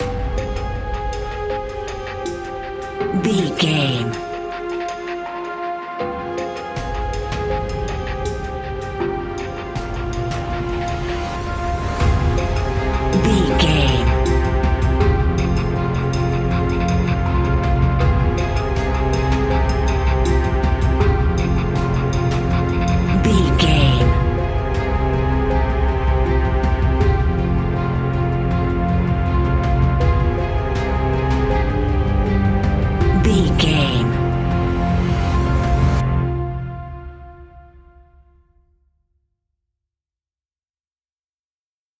Aeolian/Minor
ominous
dark
eerie
strings
drums
percussion
synthesiser
electric piano
horror music
Horror Pads